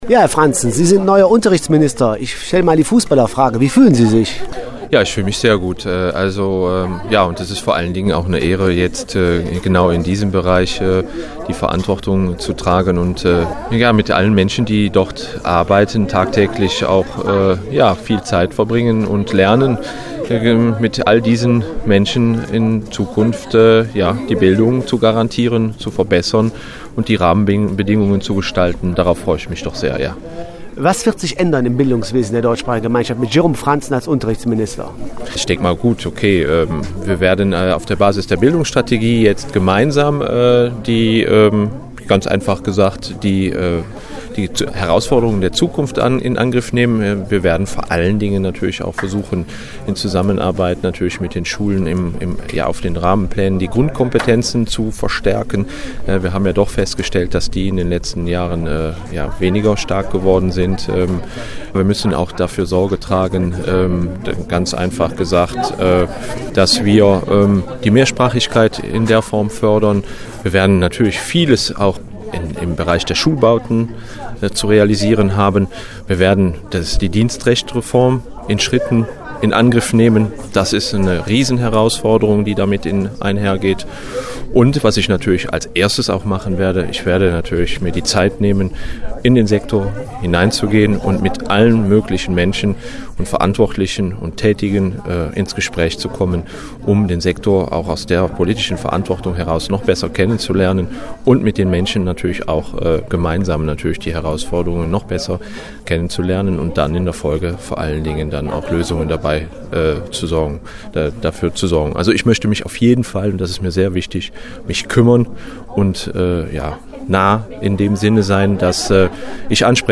hat mit ihm gesprochen.